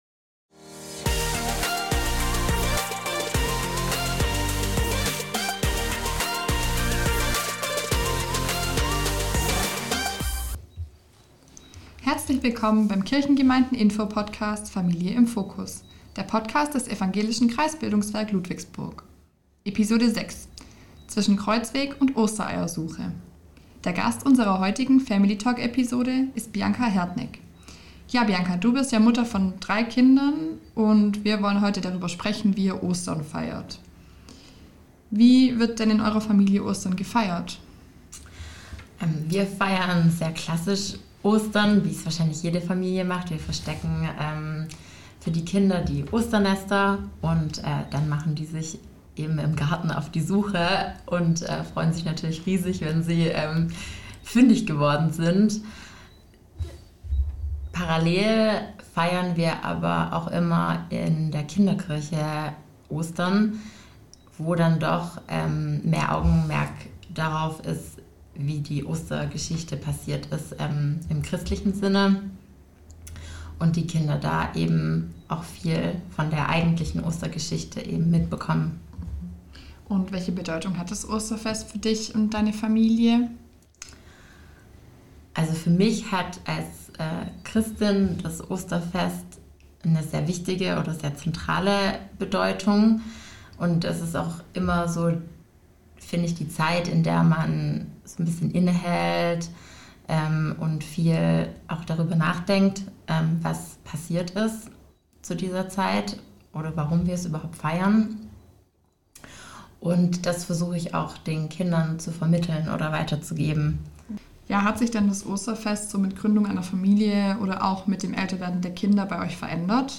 Diese Episode enthält unseren ersten Family-Talk.